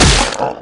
SH_hit.ogg